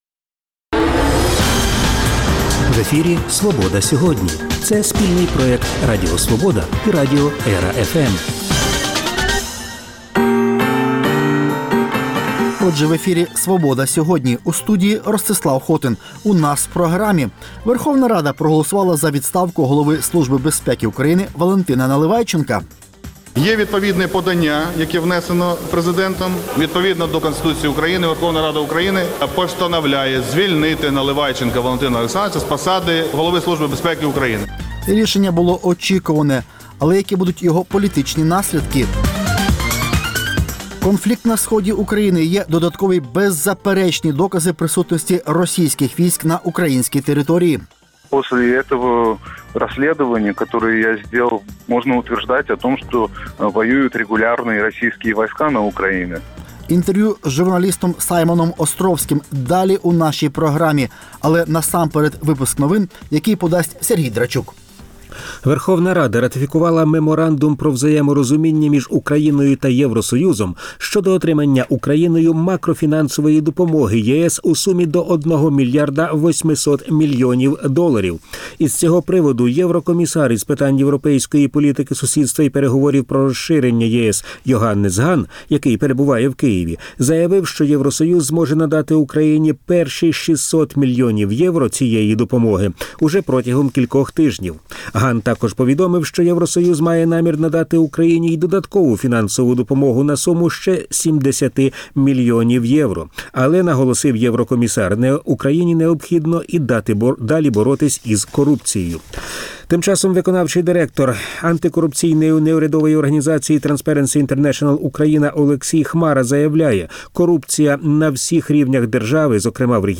*Інтерв’ю з журналістом Саймоном Островським про ситуацію на Сході України